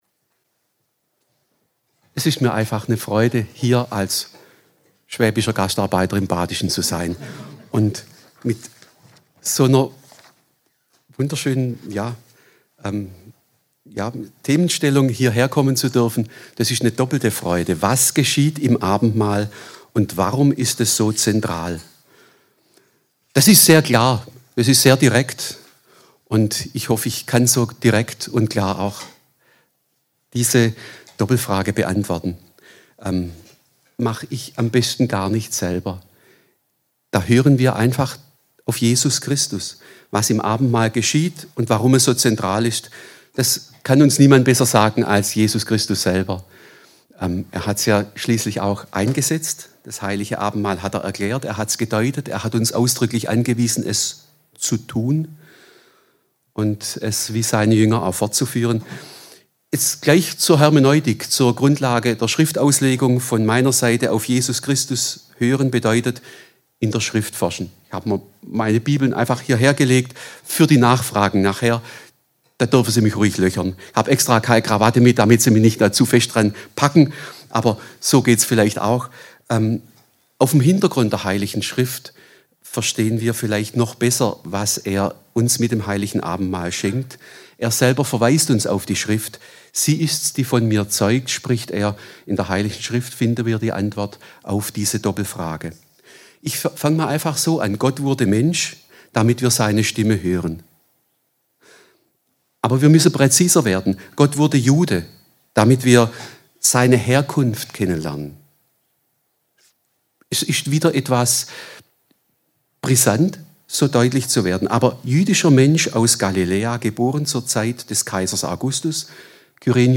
Lehrabend – Das Heilige Abenmahl – Was geschieht im Abendmahl – und warum ist es so zentral?